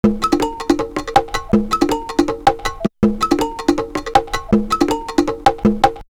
2_DrumLoops_2.wav